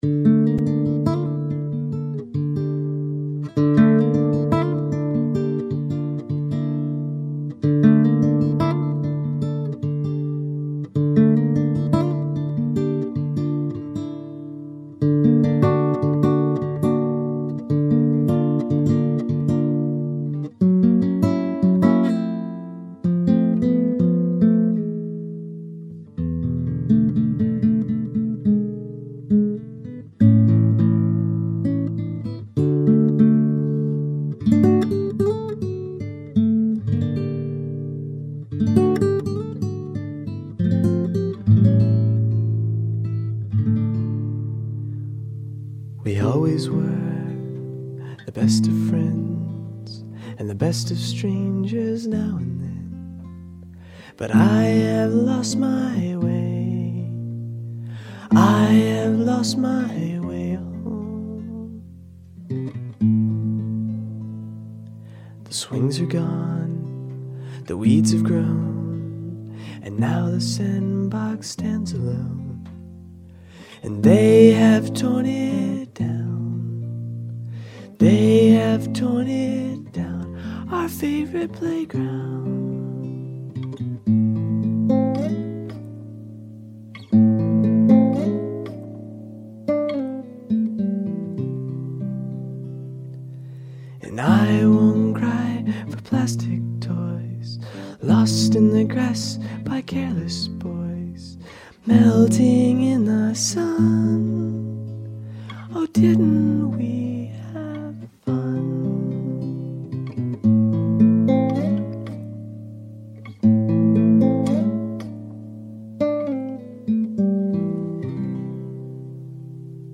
Original folk, international folk and traditional gospel.